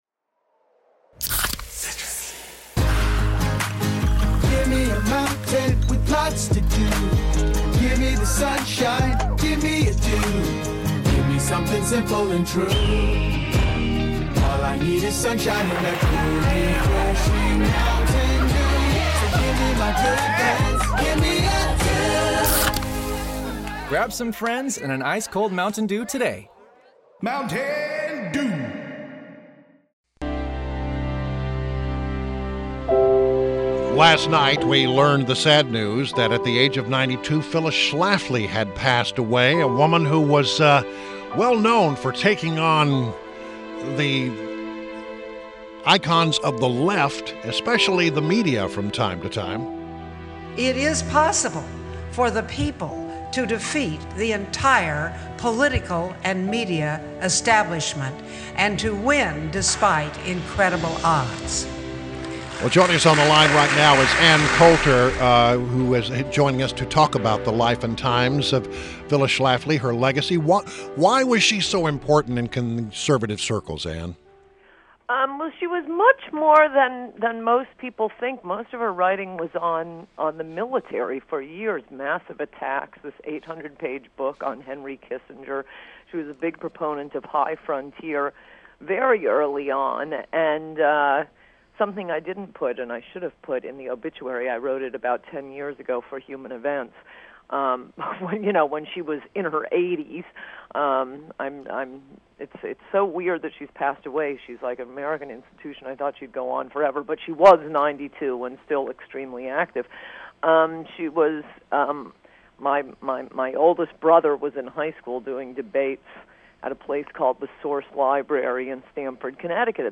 WMAL Interview - ANN COULTER - 09.06.16